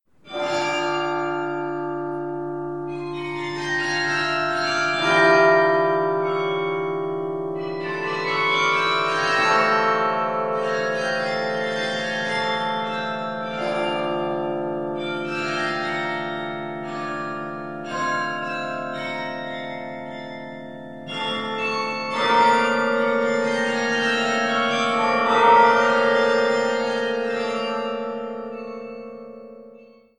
CARILLON_SW.KATARZYNA.mp3